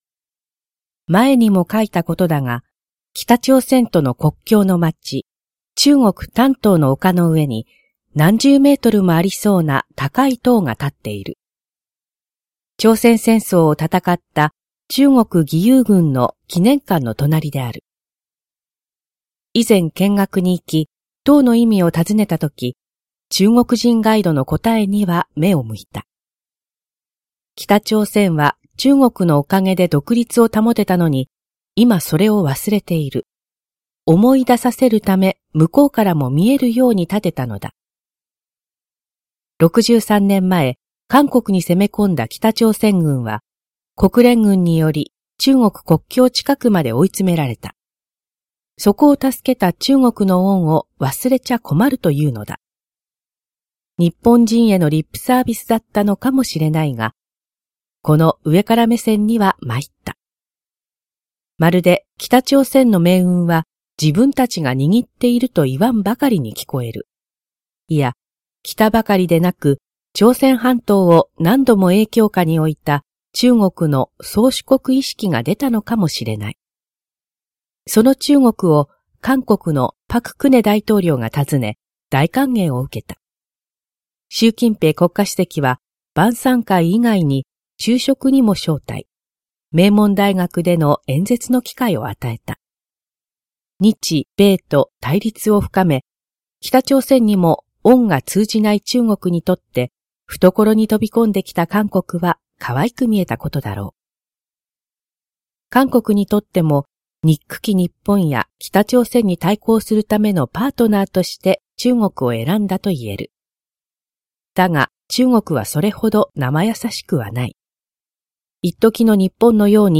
全国240名の登録がある局アナ経験者がお届けする番組「JKNTV」
産経新聞1面のコラム「産経抄」を、局アナnetメンバーが毎日音読してお届けします。